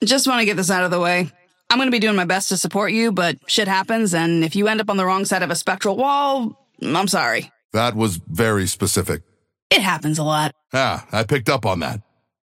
Abrams and McGinnis conversation 1